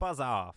Voice Lines / Dismissive
buzz off.wav